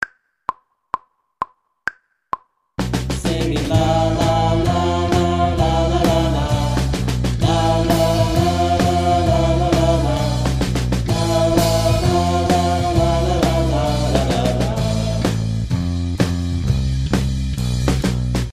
Passenger vocals sections 4 and 7